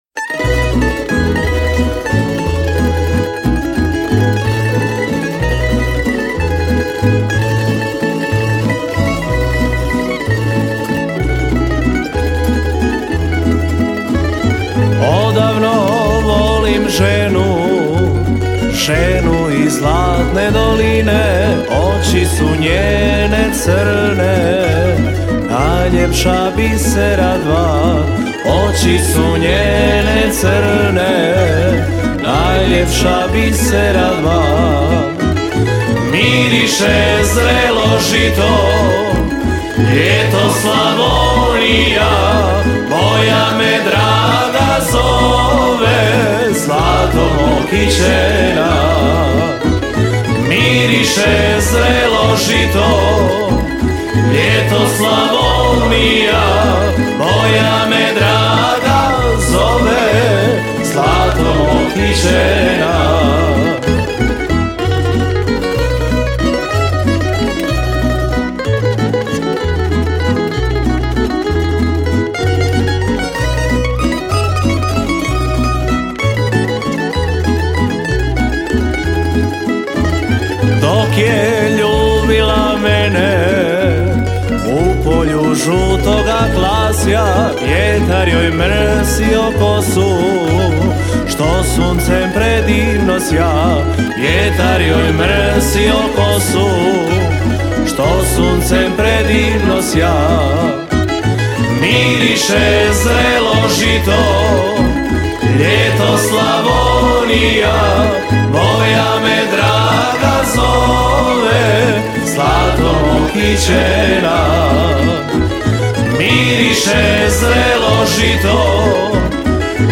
37. Festival pjevača amatera
Zvuci tamburice do kasnih noćnih sati odzvanjali su prepunom dvoranom vatrogasnog doma u Kaptolu.